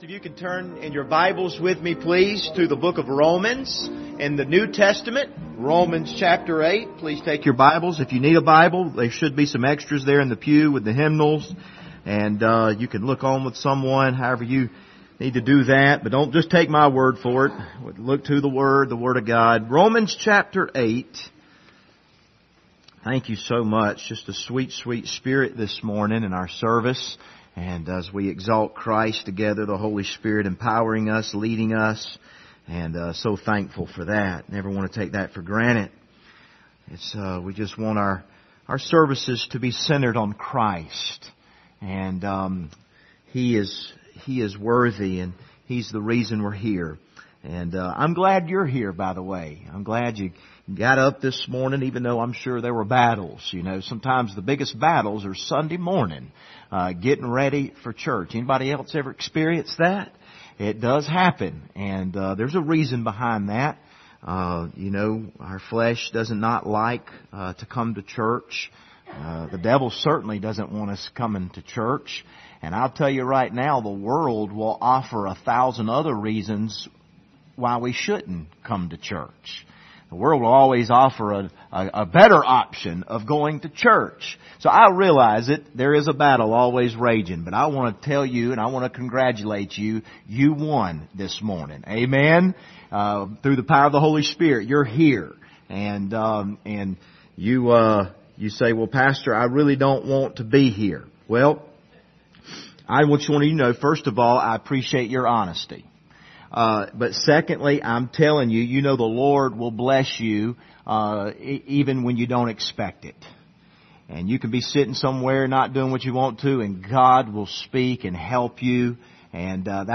Passage: Romans 8:4-11 Service Type: Sunday Morning